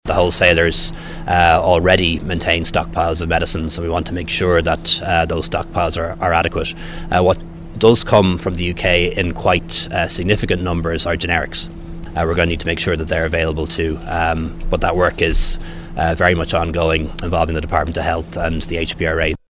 Leo Varadkar says several State agencies are in discussions about stockpiling: